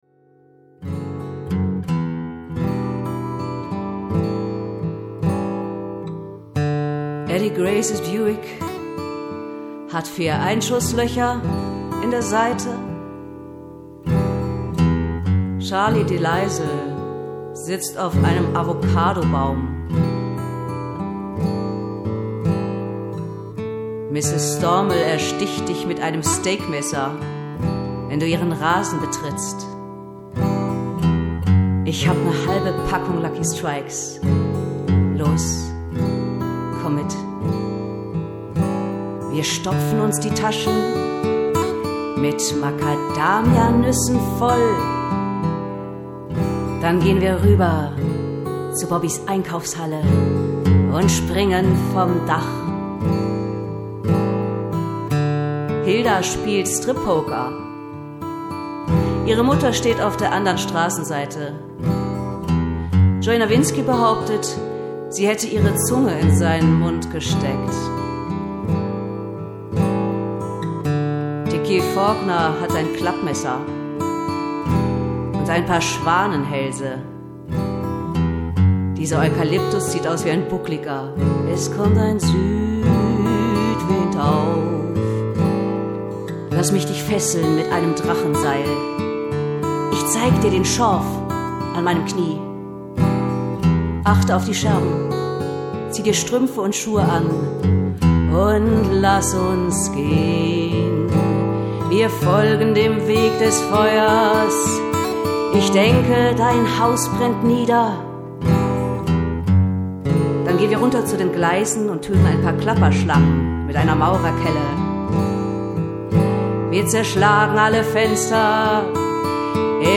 Gesang
A-Gitarren, E-Gitarren, Banjo, Blues-Harp & Percussion